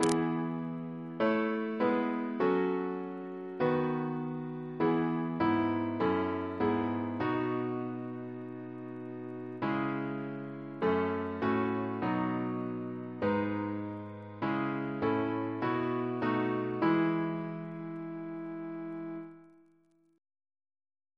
Double chant in E Composer: Anonymous Reference psalters: H1982: S252